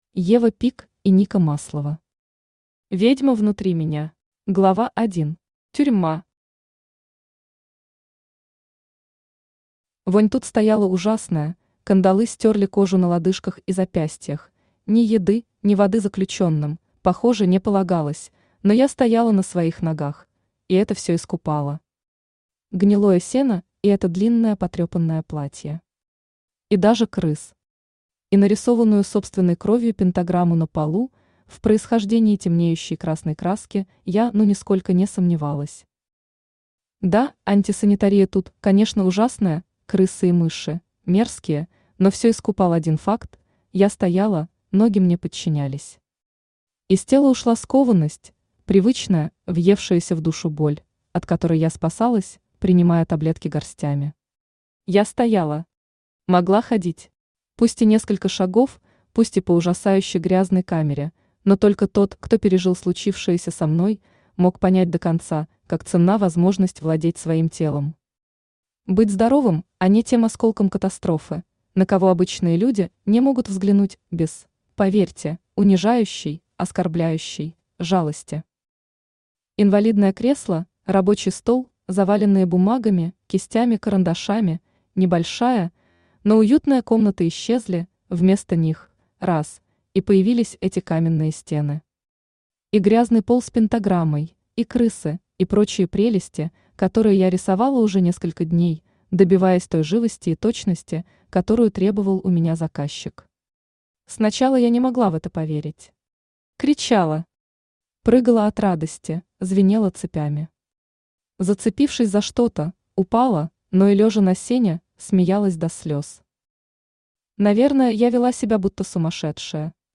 Аудиокнига Ведьма внутри меня | Библиотека аудиокниг
Aудиокнига Ведьма внутри меня Автор Ева Пик и Ника Маслова Читает аудиокнигу Авточтец ЛитРес.